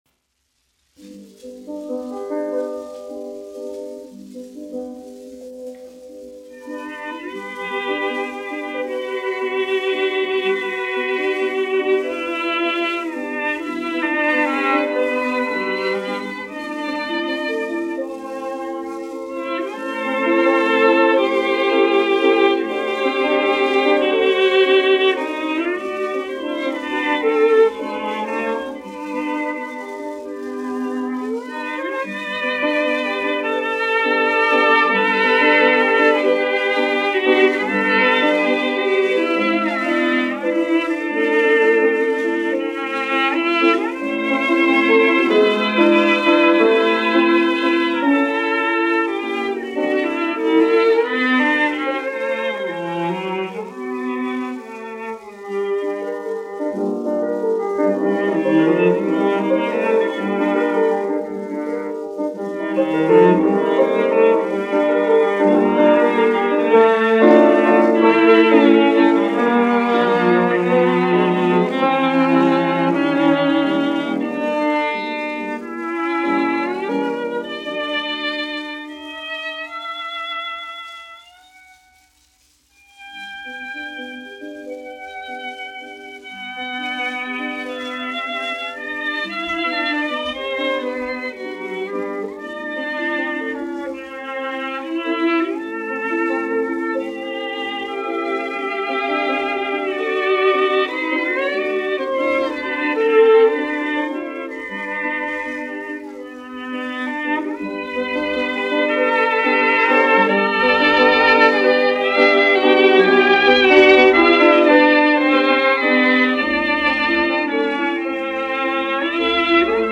1 skpl. : analogs, 78 apgr/min, mono ; 25 cm
Klavieru trio, aranžējumi
Latvijas vēsturiskie šellaka skaņuplašu ieraksti (Kolekcija)